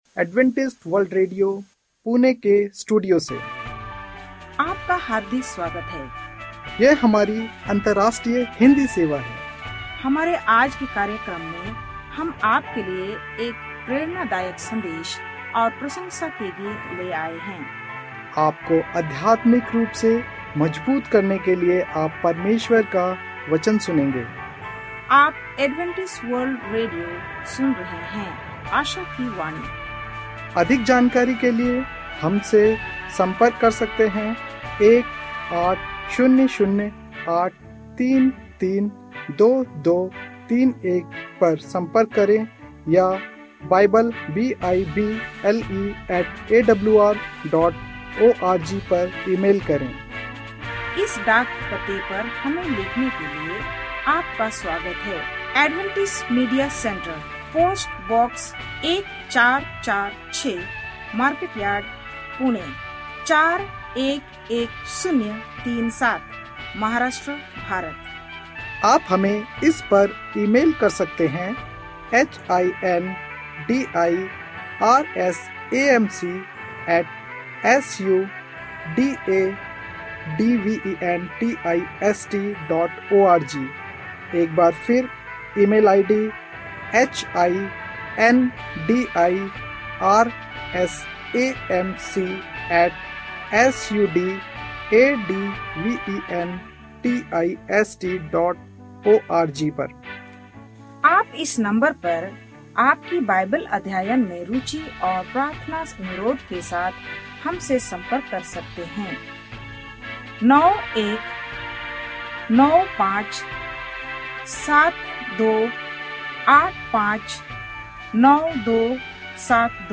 Hindi radio program from Adventist World Radio